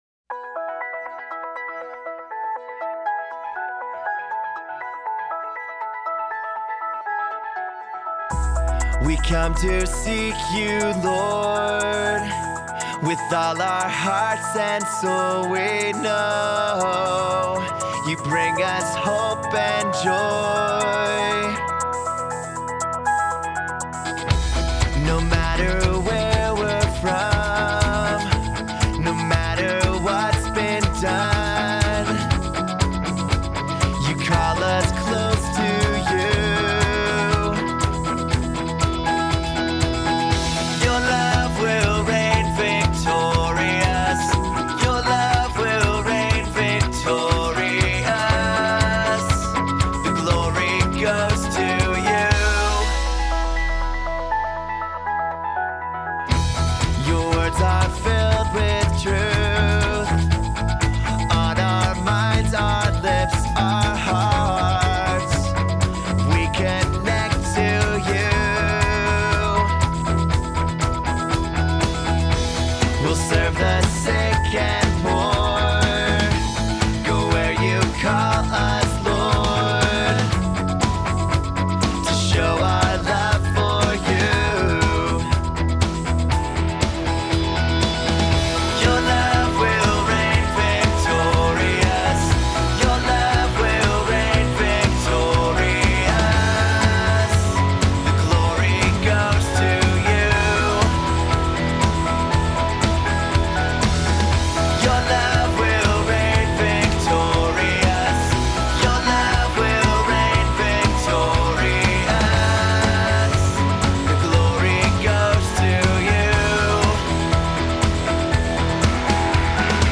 Voicing: Two-part choir; Cantor; Assembly